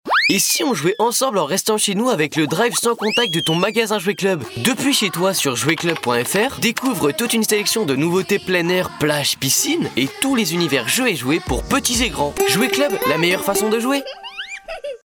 VOIX JEUNE (sur demande)